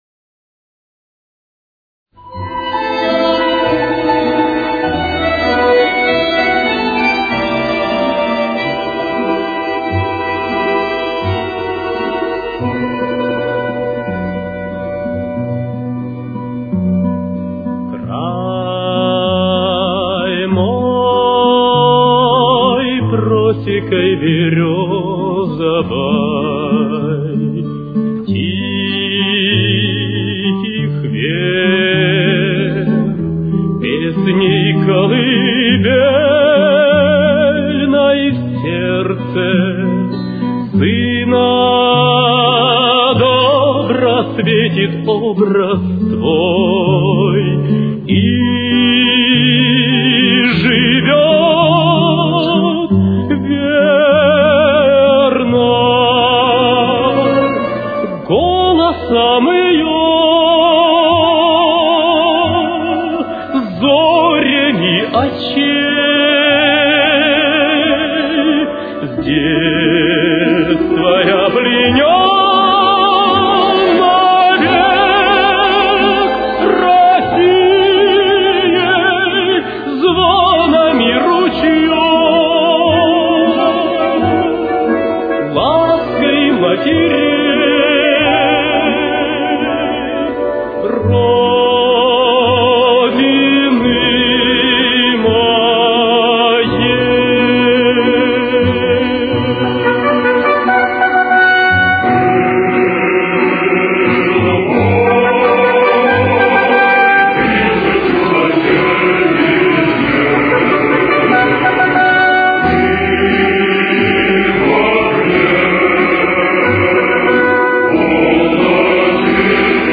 Темп: 94.